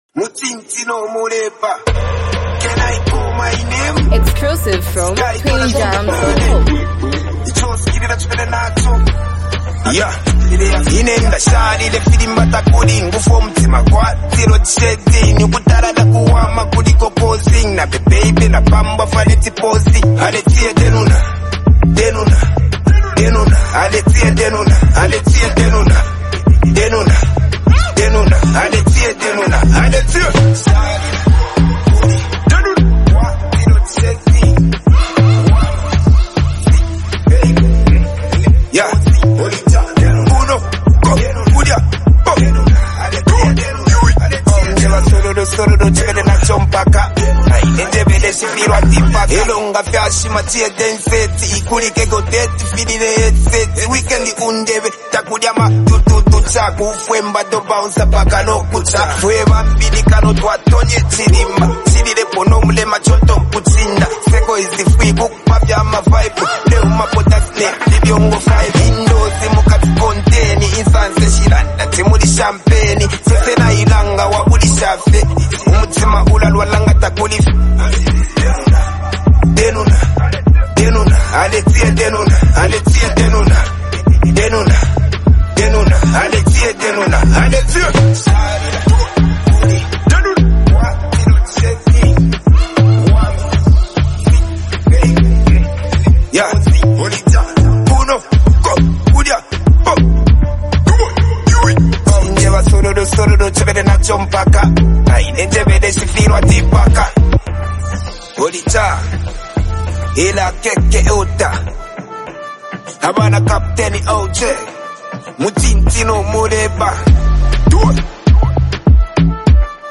Highly multi talented act and super creative rapper r